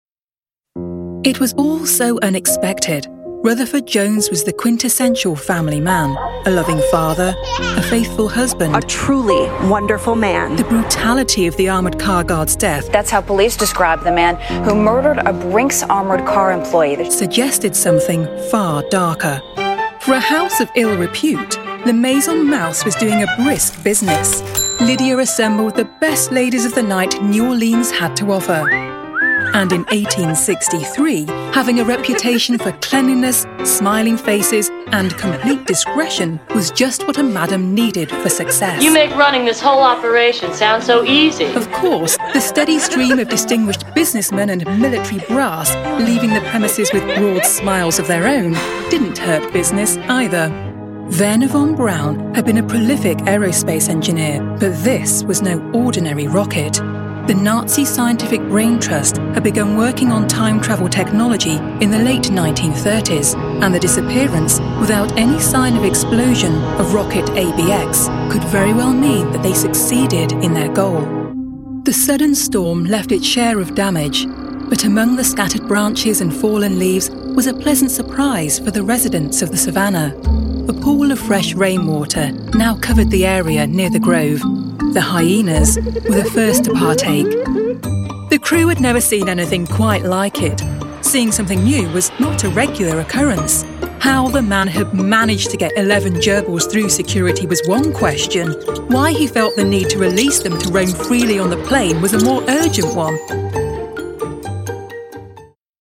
Female
Assured, Authoritative, Confident, Corporate, Engaging, Friendly, Gravitas, Natural, Reassuring, Smooth, Soft, Warm, Witty, Conversational
Corporate.mp3